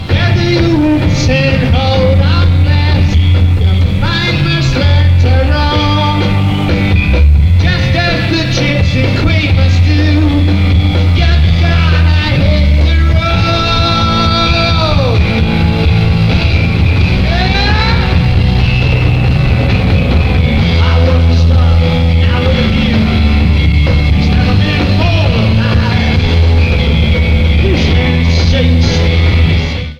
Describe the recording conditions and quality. Format/Rating/Source: CD - D- - Audience Comments: Poor sound quality. Sound Samples (Compression Added):